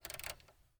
Звук скольжения пальцами по экрану